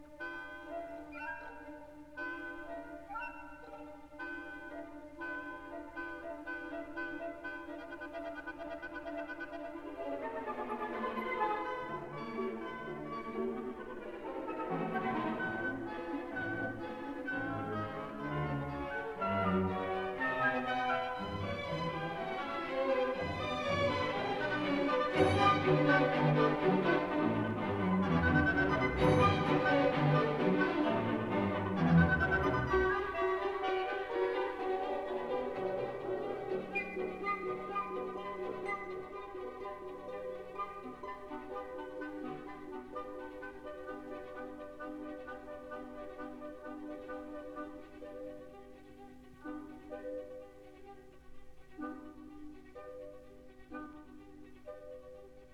Vivace con spirito